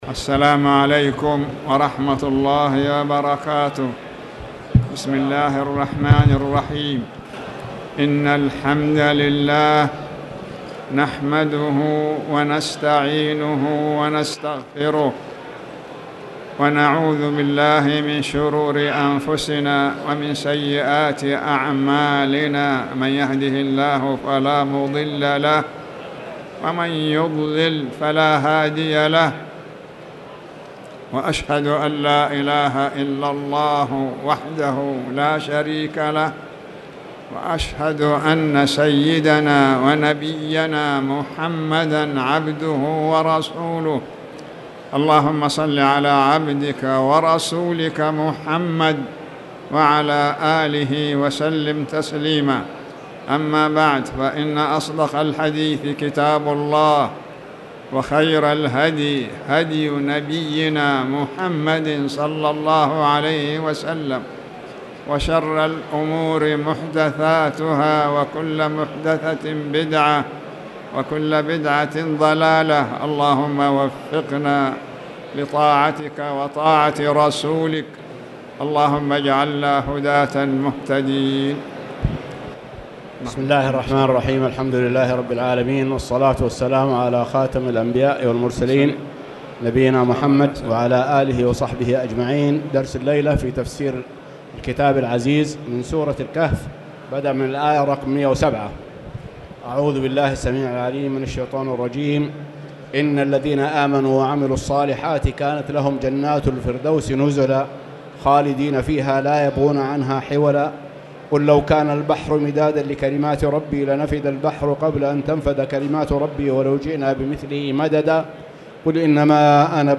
تاريخ النشر ٢٨ صفر ١٤٣٨ هـ المكان: المسجد الحرام الشيخ